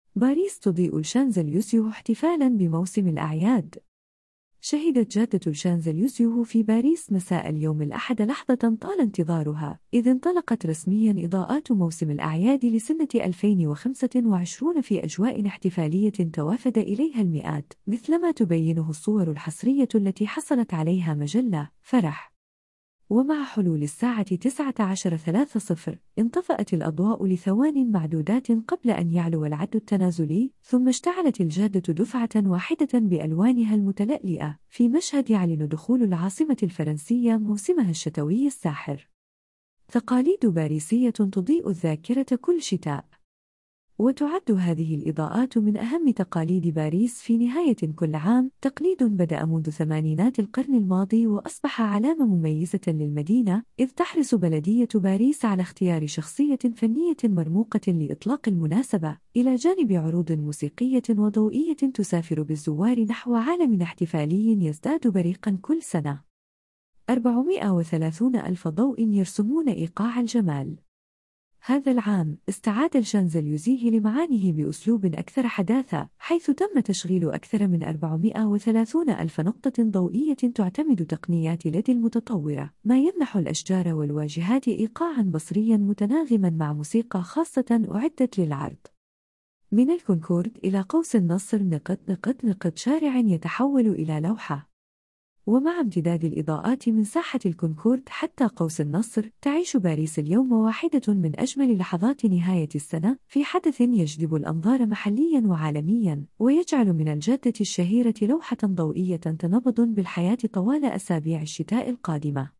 شهدت جادة الشانزليزيه في باريس مساء اليوم الأحد لحظة طال انتظارها؛ إذ انطلقت رسميًا إضاءات موسم الأعياد لسنة 2025 في أجواء احتفالية توافَد إليها المئات، مثلما تبينه الصور الحصرية التي حصلت عليها مجلة (فرح).
ومع حلول الساعة 19 :30، انطفأت الأضواء لثوانٍ معدودات قبل أن يعلو العدّ التنازلي، ثم اشتعلت الجادة دفعة واحدة بألوانها المتلألئة، في مشهد يعلن دخول العاصمة الفرنسية موسمها الشتوي الساحر.